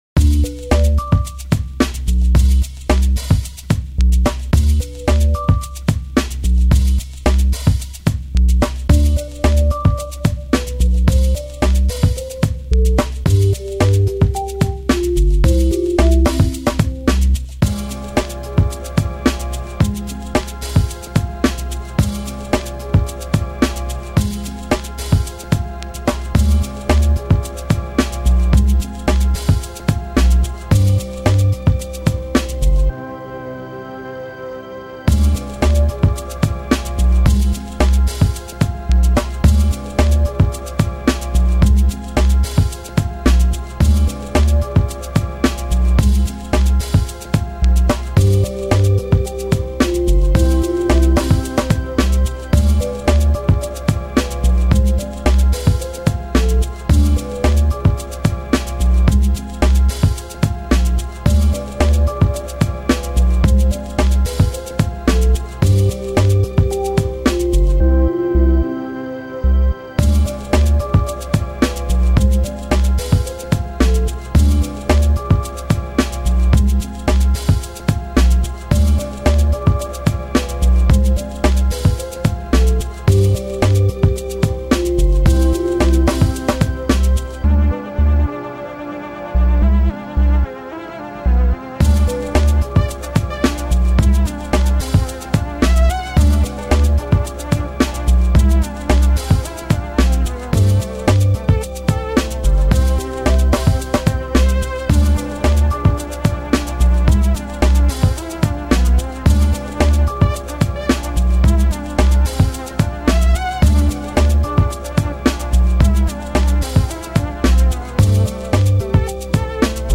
I added some other noises and sounds.
Filed under: Instrumental | Comments (3)
I liked the retro sound of the string samples, a way to get an old sound without always resorting to mellotron plugins.
I like the record pops.
this is very smooth and clean, I like it, good choice in samples, it feels very DJ shadowis, only better :)